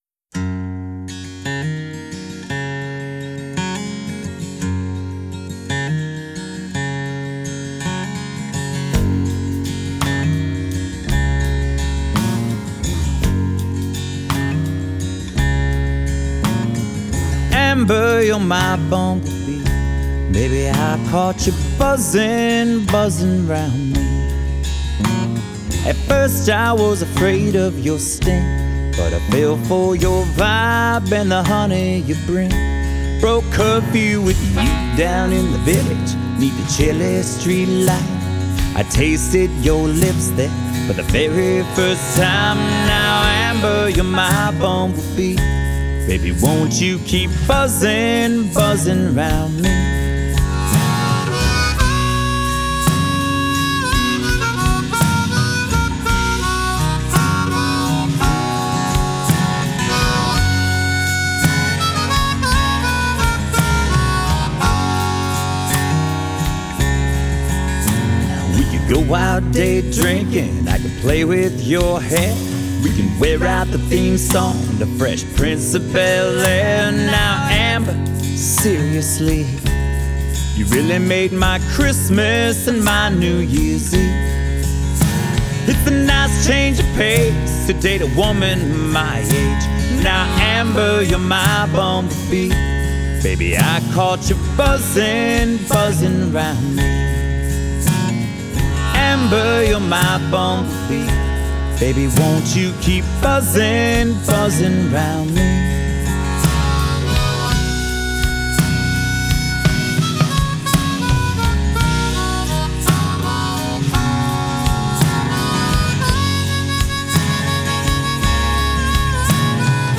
an acoustic and harmonica driven love song in 3/4.